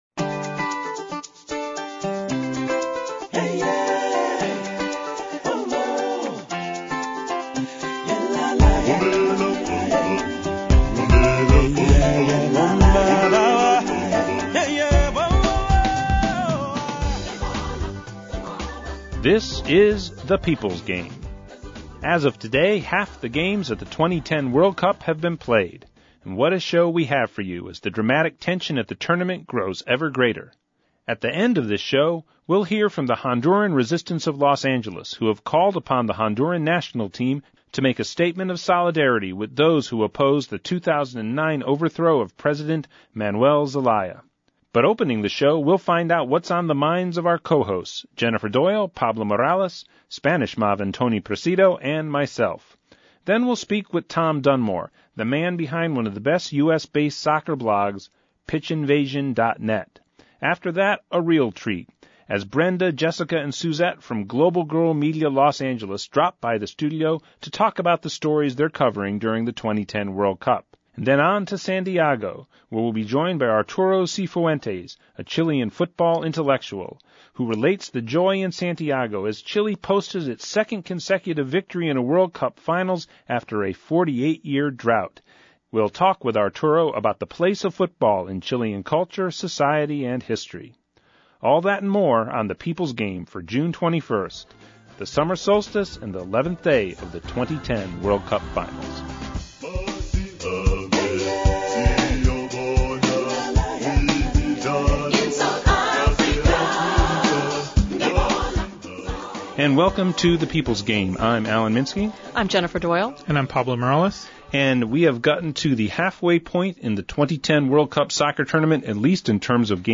The People’s Game – Radio Show for Monday June 21, 2010